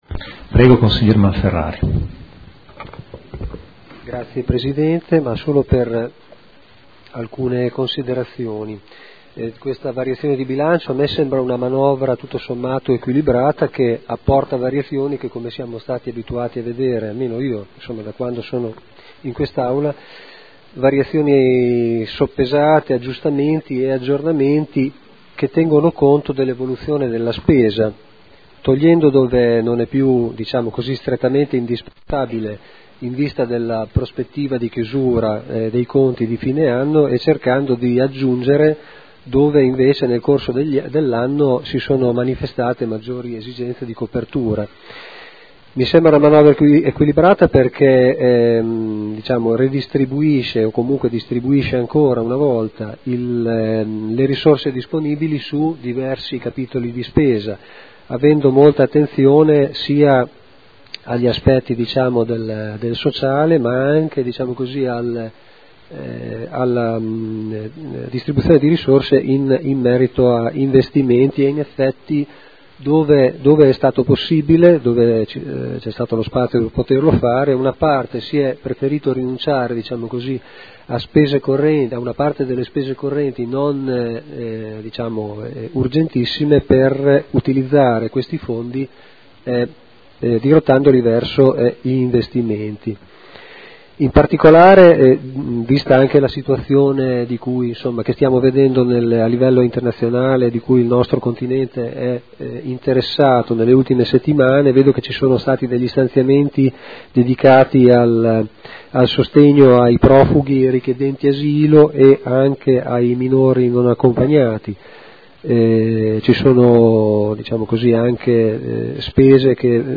Marco Malferrari — Sito Audio Consiglio Comunale
Dibattito